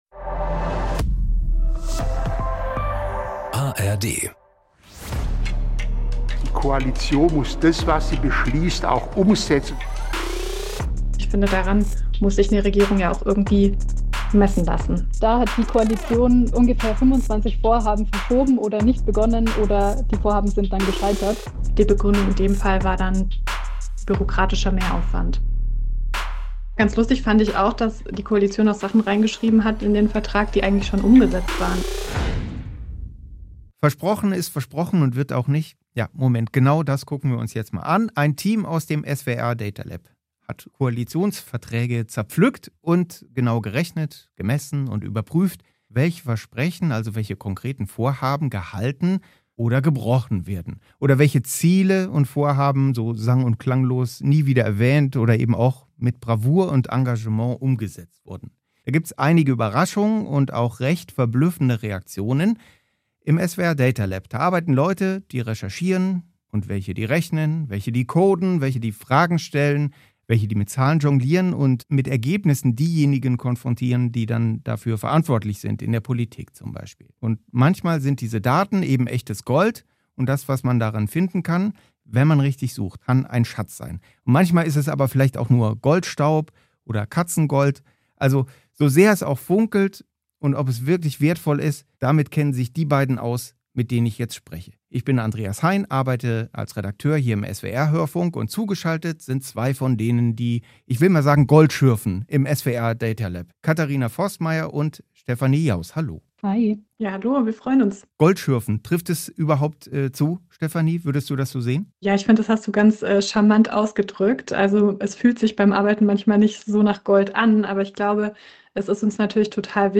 Eine Woche vor Landtagswahl: Politiker stellen sich bei Wahlarena Publikumsfragen
Die Spitzenkandidaten von Grünen, CDU, SPD, FDP, AfD und Linken stellen sich Fragen aus dem Publikum.